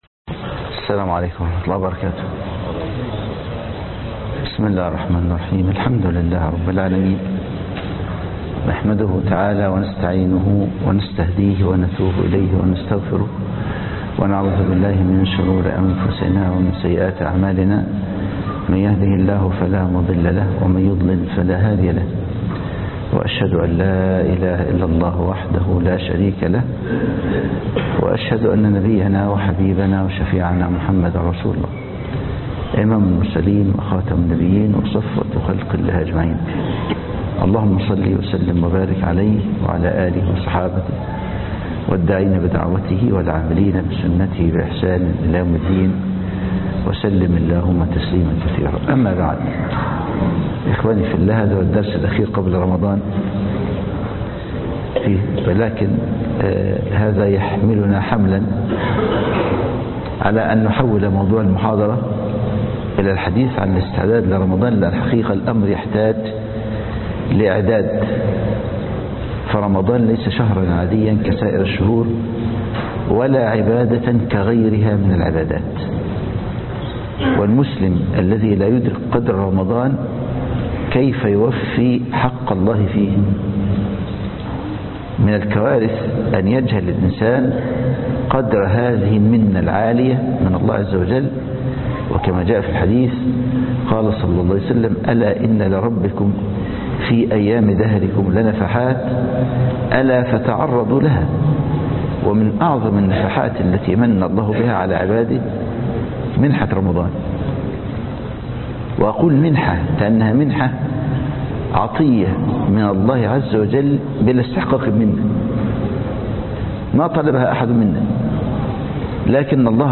الاستعداد لرمضان (مسجد العطية)